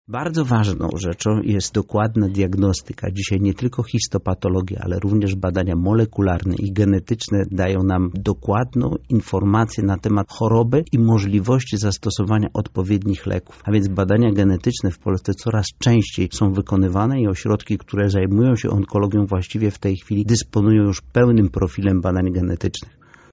• wyjaśnia prof. Mariusz Bidziński, krajowy Konsultant w dziedzinie Ginekologii Onkologicznej.